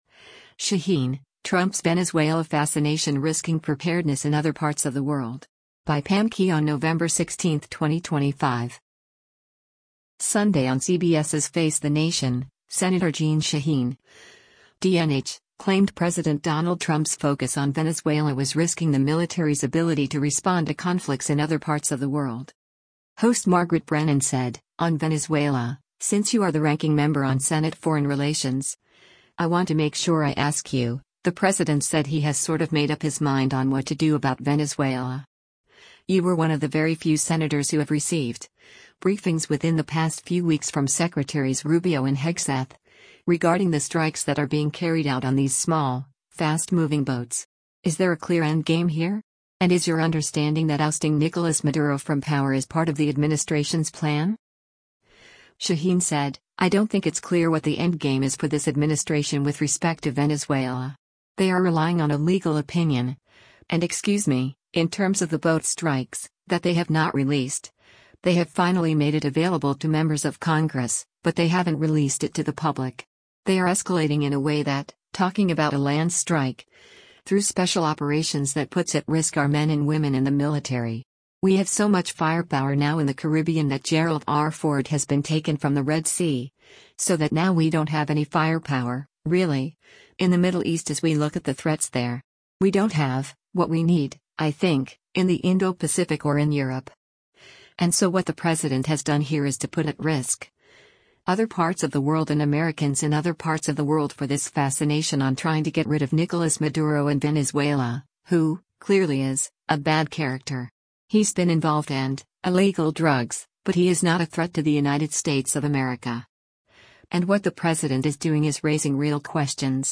Sunday on CBS’s “Face the Nation,” Sen. Jeanne Shaheen (D-NH) claimed President Donald Trump’s focus on Venezuela was risking the military’s ability to respond to conflicts in other parts of the world.